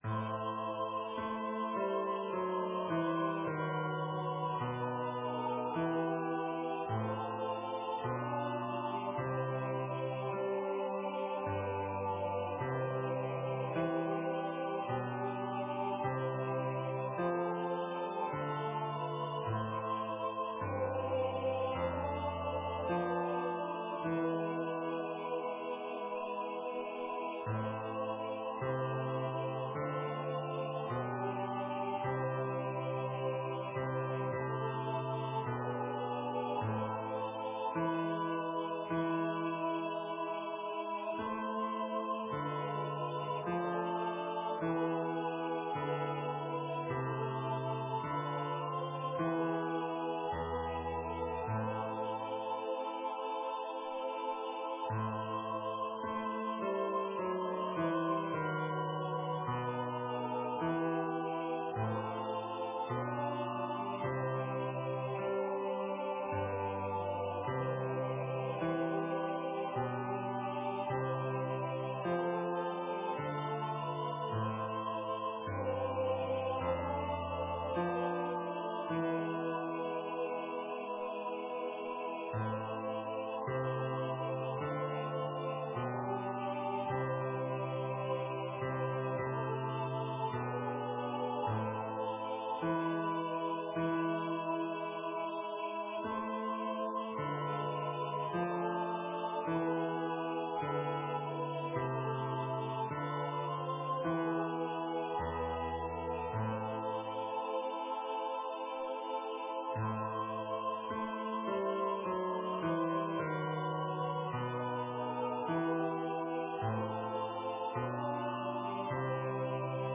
with Accompaniment
(SATB Version)
MP3 Practice Files: Soprano:   Alto:   Tenor:   Bass:
Number of voices: 4vv   Voicing: SATB
Genre: SacredChorale
Instruments: Piano